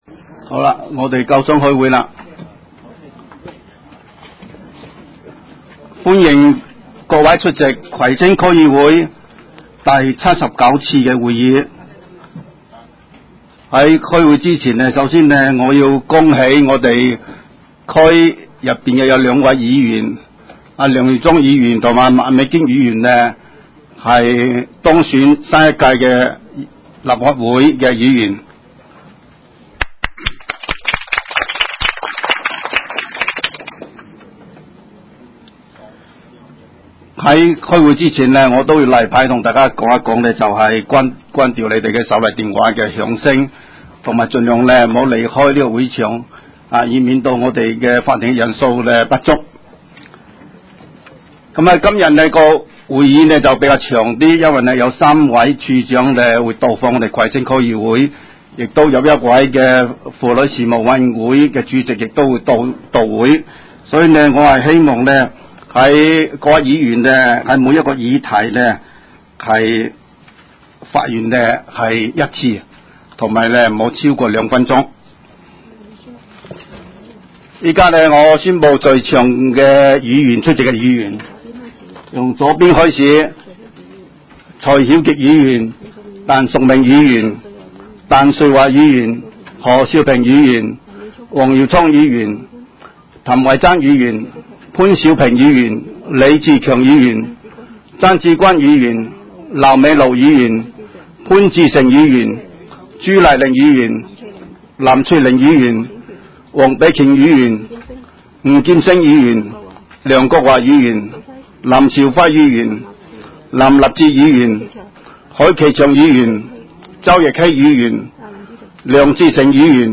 区议会大会的录音记录
开会词